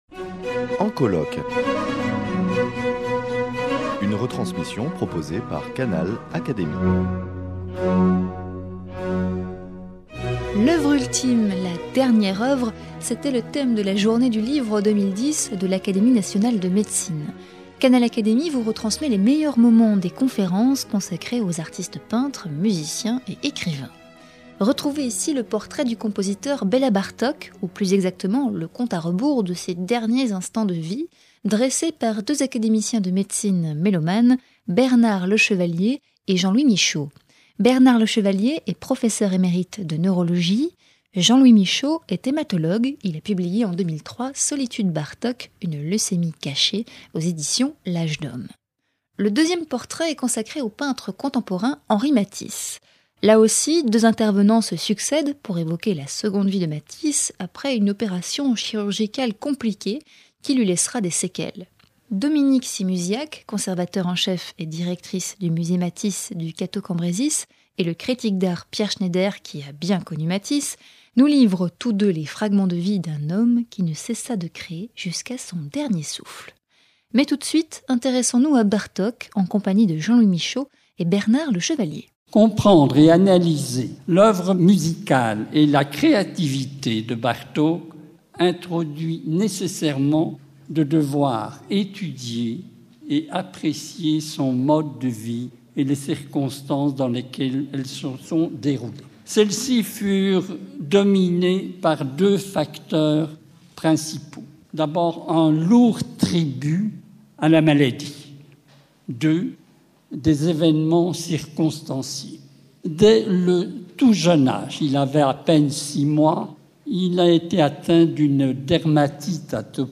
La "dernière œuvre" était l’objet de la journée du livre de l’Académie nationale de médecine 2010, dont Canal Académie retransmet sur son site les meilleurs moments.